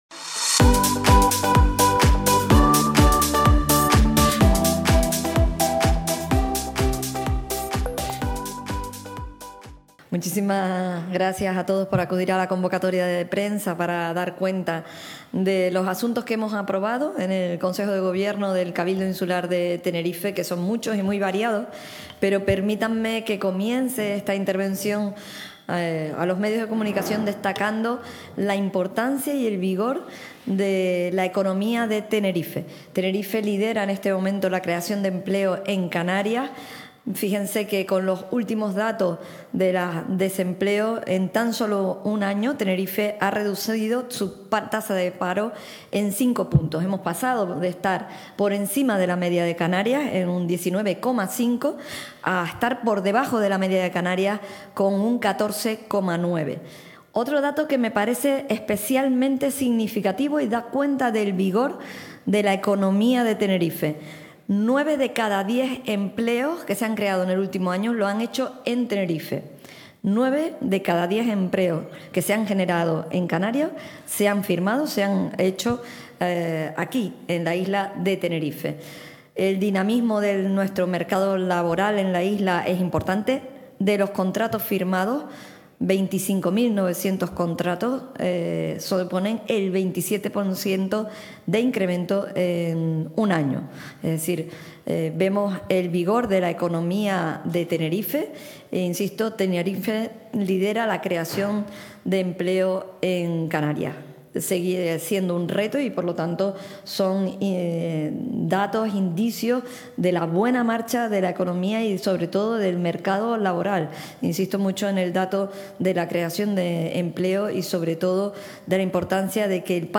Canal Tenerife TV | Presentación de los Acuerdos del Consejo de Gobierno, 8 de mayo de 2024